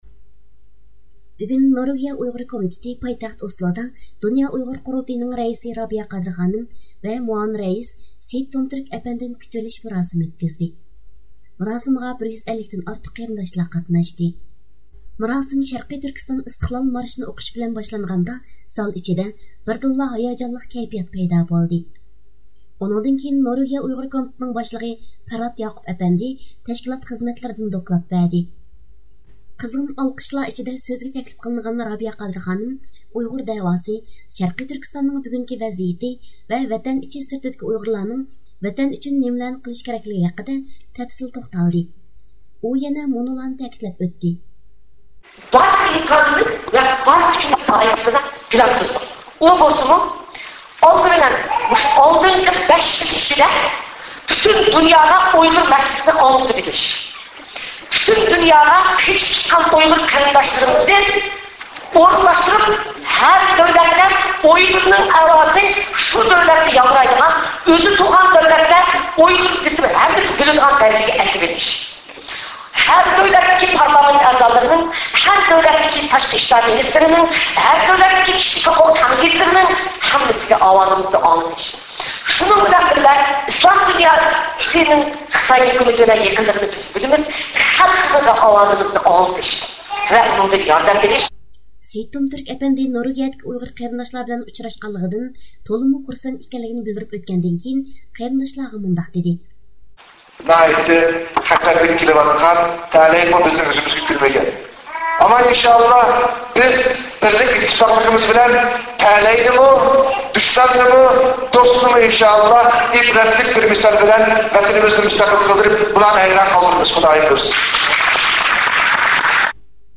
رابىيە قادىر خانىم نورۋېگىيىدىكى ئۇيغۇرلارغا نۇتۇق سۆزلىدى – ئۇيغۇر مىللى ھەركىتى
مۇراسىمغا 150 تىن ئارتۇق ئادەم قاتناشتى.